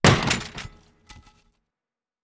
ladder-break.wav